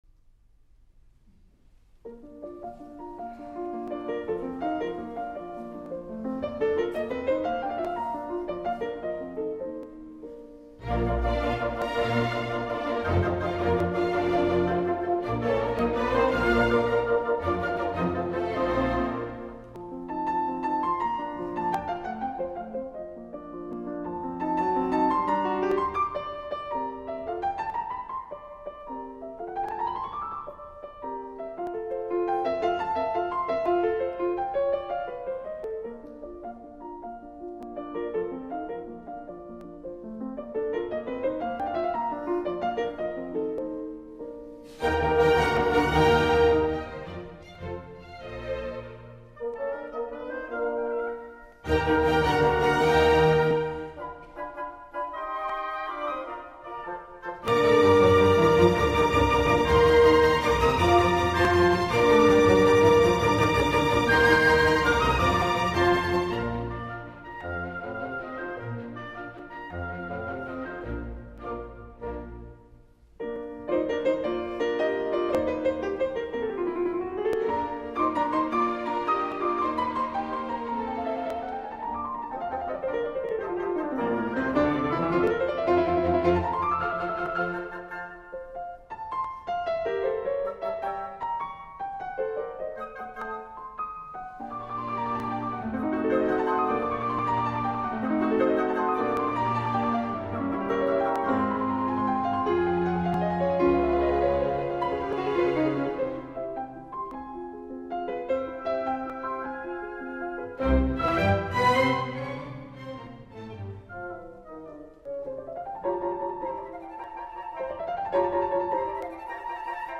Ja em perdonareu, però avui em faig un apunt per a mi i si algú es vol apuntar a la teràpia Mozart,  amb les meravelloses píndoles servides per la gran i immensa Maria Joao Pires acompanyada per la London Symphony Orchestra, sota la direcció de Bernard Haitink, aquí ho teniu.
Us deixo el concert per a piano i orquestra número 27 en Si bemoll major de Wolfgang Amadeus Mozart, amb els seus tres moviments
El concert es va celebrar el passat 16 de juny de 2011 al Barbican Center de Londres.
Us deixo l’Alegro final, és realment terapèutic.